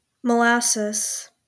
Molasses (/məˈlæsɪz, m-/